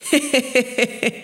Mujer riendo 2
carcajada
mujer
risa
Sonidos: Acciones humanas
Sonidos: Voz humana